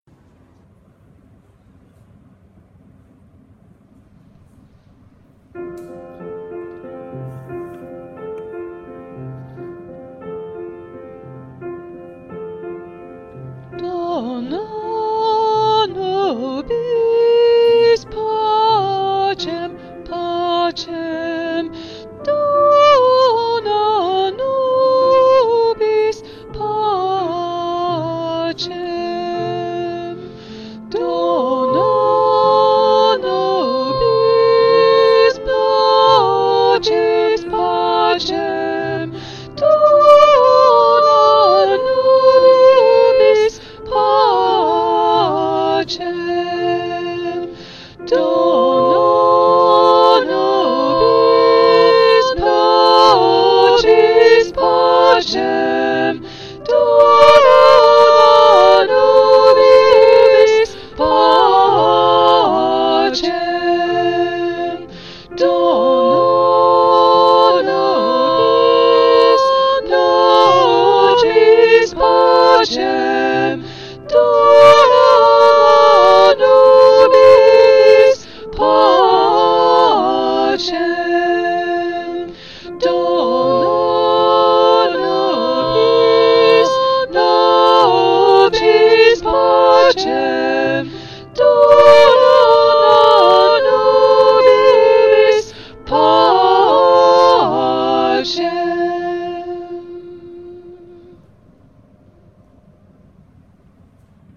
Good morning and welcome to our joint service of holy communion for Sunday 9 July
During communion the choir sang “Dona Nobis Pacem”: